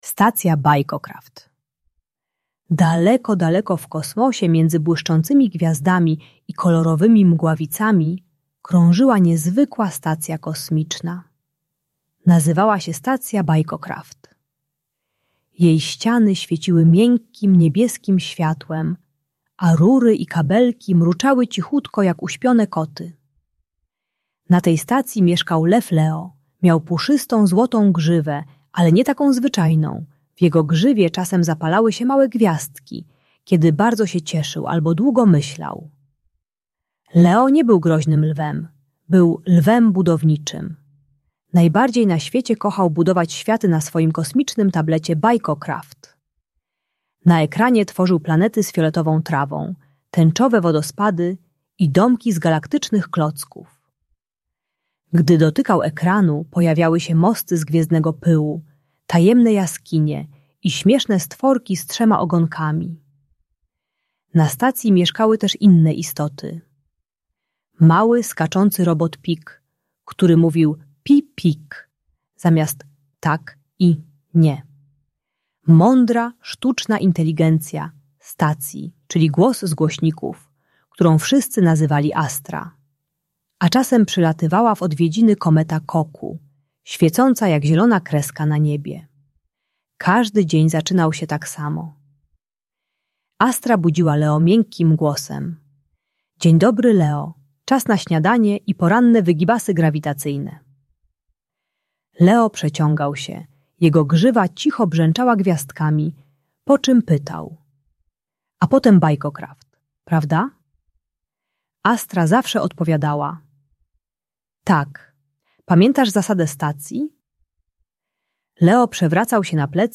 Bajka dla dziecka które nie chce odłożyć tabletu i gra za długo w gry. Audiobajka o uzależnieniu od ekranów dla dzieci 4-7 lat uczy techniki 3 głębokich oddechów i samodzielnego robienia przerw od gier.